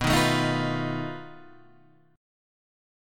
B 7th Flat 5th